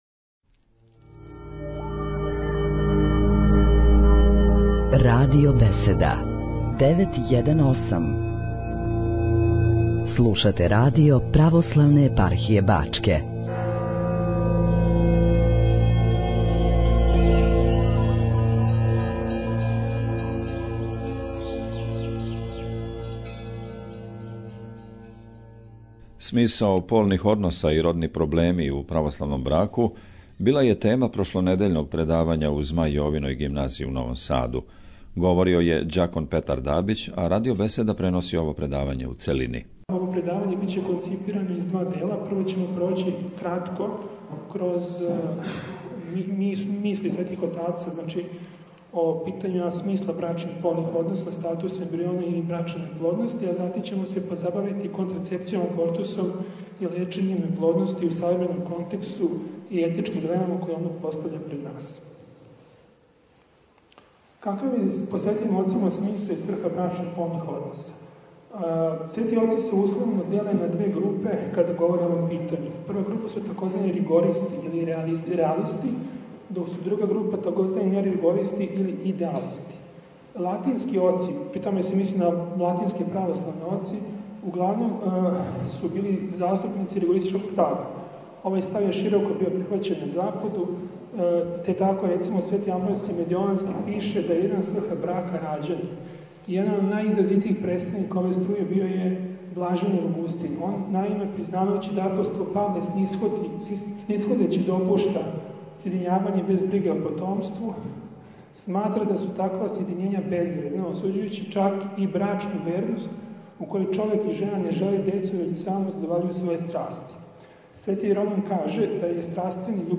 Затим је уследила интересaнтна дискусија, у којој je учешћa узеo Преосвећени Eпископ бачки господин др Иринеј, као и присутни слушаоци.
Звучни запис предавања